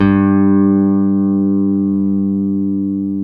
Index of /90_sSampleCDs/Roland L-CDX-01/BS _Rock Bass/BS _Stretch Bass